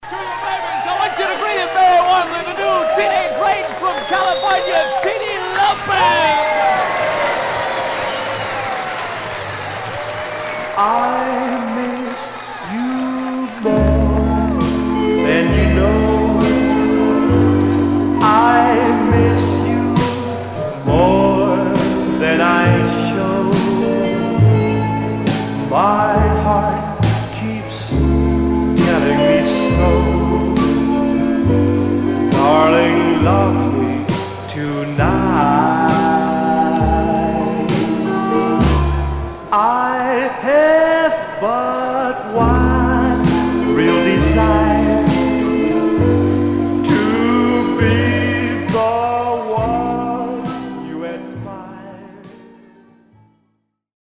Condition Surface noise/wear Stereo/mono Mono
Teen